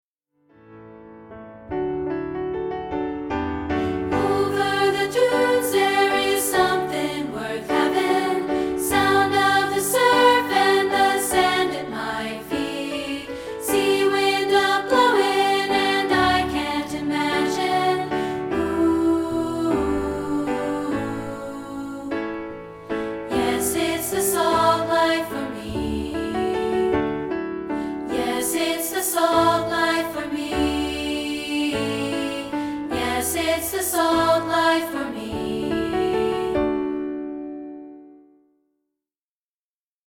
including this rehearsal track for part 2B.